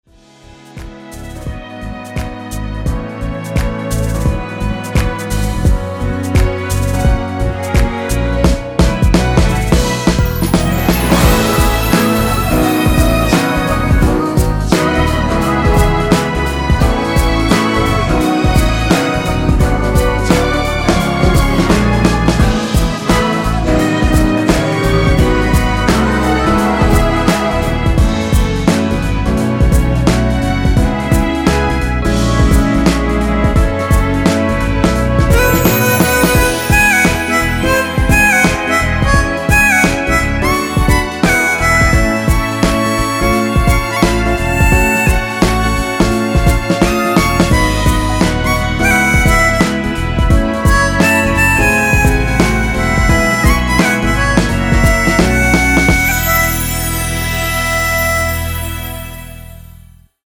엔딩이 페이드 아웃이라서 노래하기 편하게 엔딩을 만들어 놓았으니 미리듣기 확인하여주세요!
원키에서(-4)내린 코러스 포함된 MR입니다.
앞부분30초, 뒷부분30초씩 편집해서 올려 드리고 있습니다.